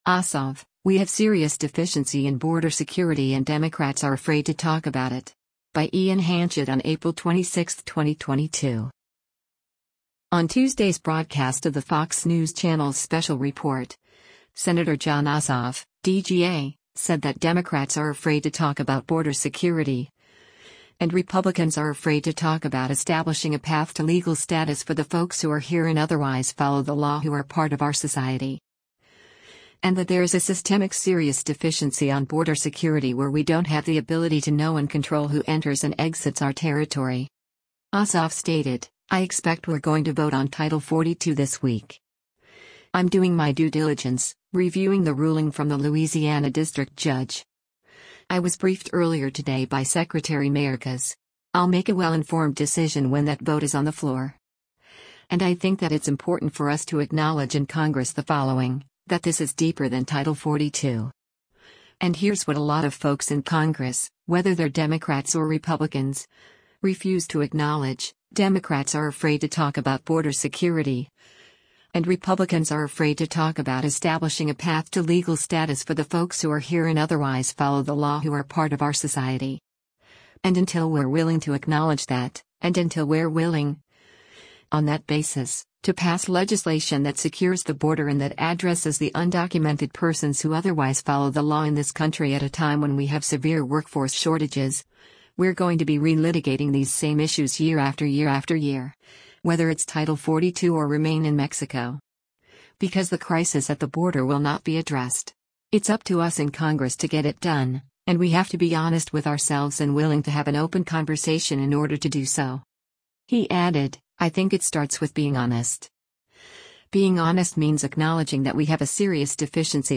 On Tuesday’s broadcast of the Fox News Channel’s “Special Report,” Sen. Jon Ossoff (D-GA) said that “Democrats are afraid to talk about border security, and Republicans are afraid to talk about establishing a path to legal status for the folks who are here and otherwise follow the law who are part of our society.” And that there is a “systemic” “serious deficiency” on border security where we don’t have the ability “to know and control who enters and exits our territory.”